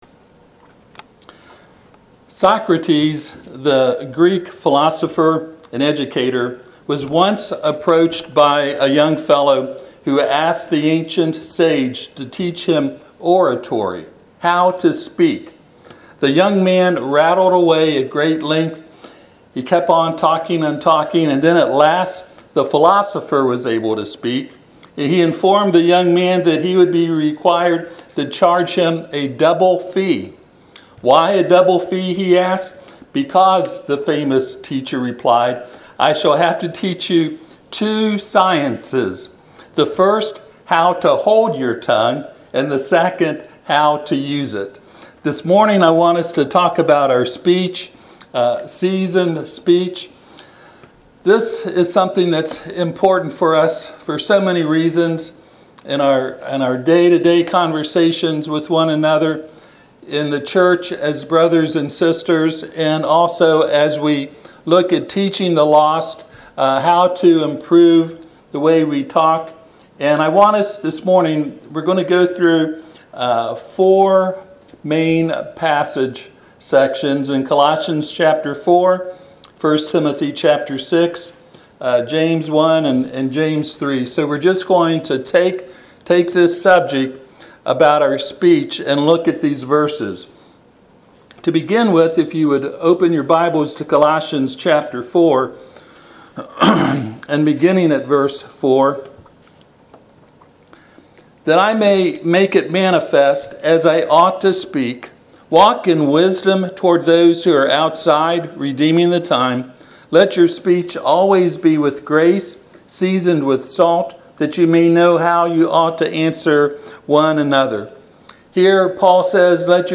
Passage: Colossians 4:4-6 Service Type: Sunday AM